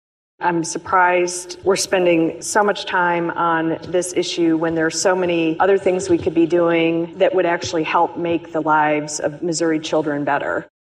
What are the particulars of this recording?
Audio provided by the Missouri Senate